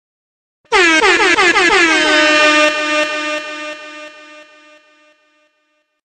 DJ Air Horn